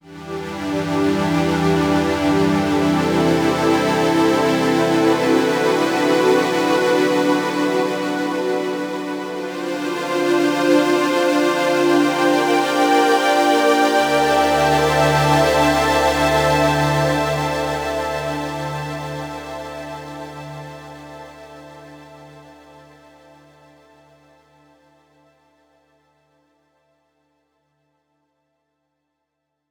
7 Hall
Für einen noch »runderen«, natürlicher und räumlicher anmutenden Sound ist bei Synthesizer Strings ein Halleffekt sehr gut geeignet.
So habe ich das feine, DSP-gestützte UAD-Plugin Universal Audio AKG BX 20 verwendet. Dieser virtuelle Federhall und die meisten anderen Reverbs klingen hier mit einer relativ dumpfen Hallfahne besser.
synthesizer_strings_tutorial_07_reverb.mp3